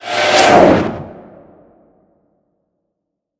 На этой странице собраны загадочные звуки порталов — от металлических резонансов до глубоких пространственных эффектов.
Портал сжался nПортал закрылся nПортал исчез